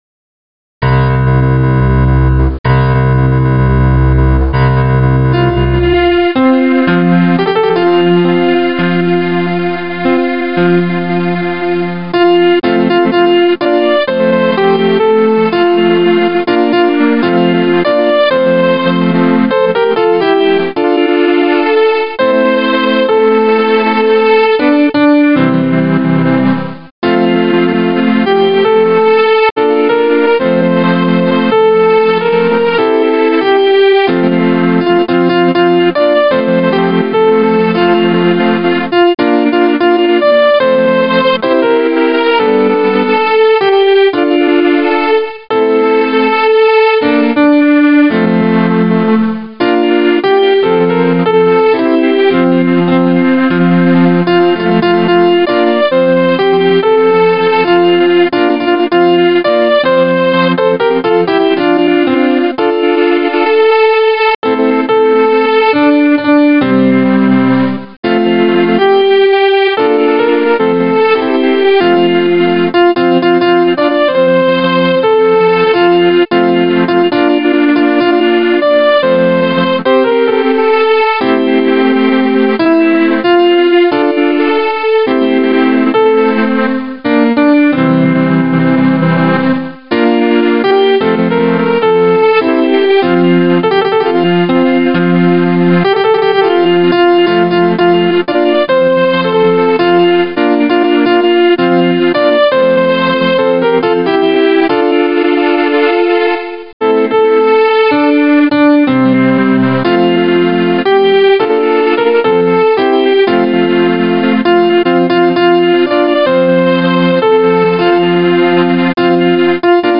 keyboard
Voice used: Grand piano